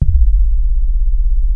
RESO C2.wav